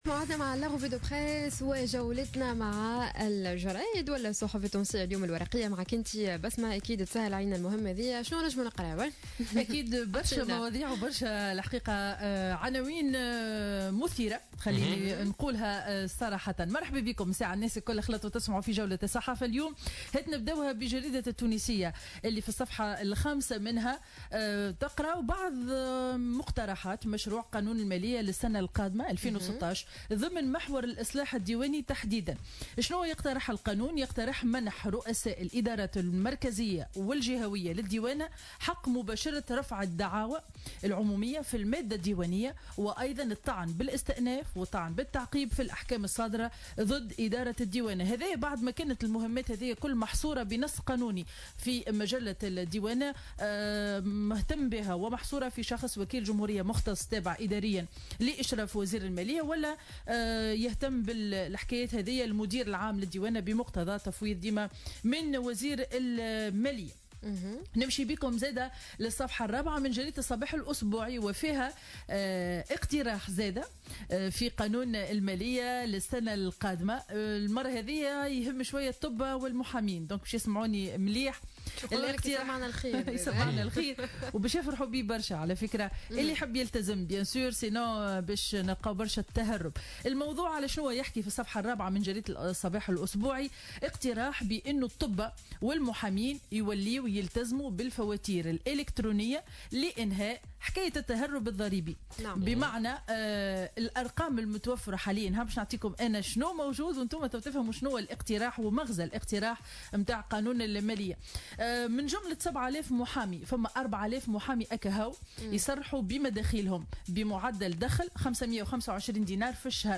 Revue de presse du lundi 19 octobre 2015